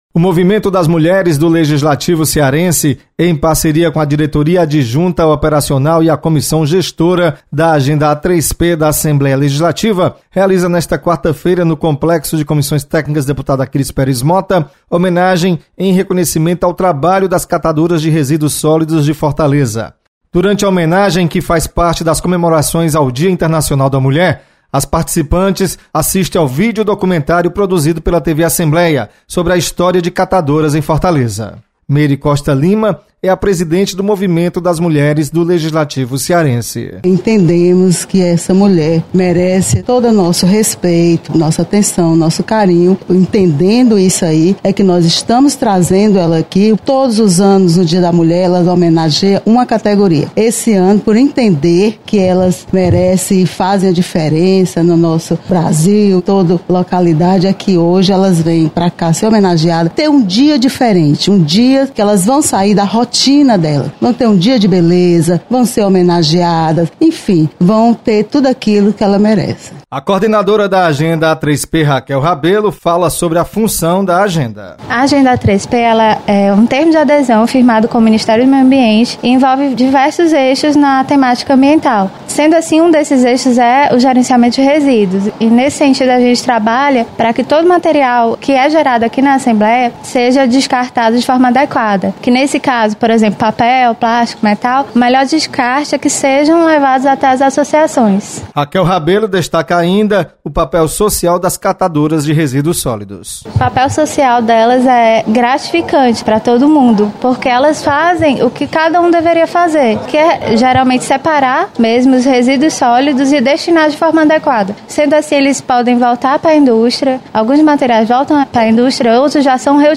Movimento das Mulheres do Legislativo Cearense presta homenagem à catadoras de lixo de Fortaleza. Repórter